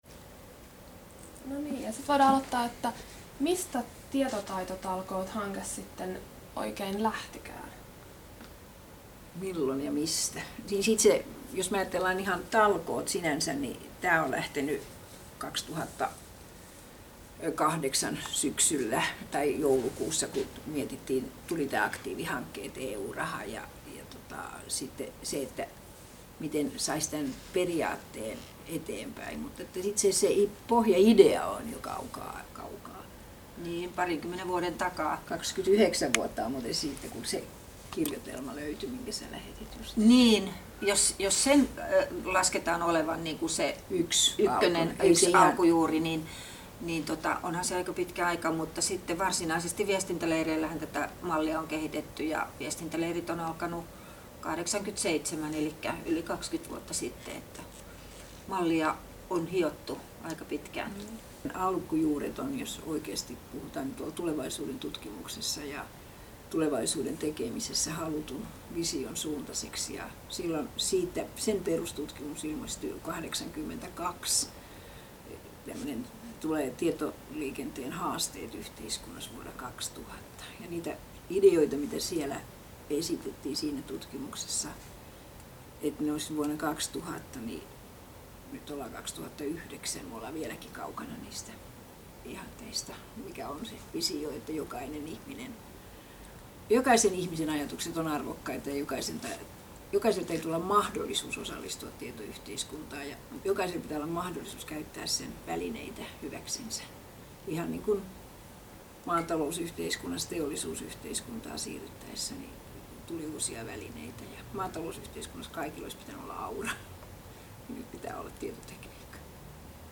Keskustelua talkoiden taustoista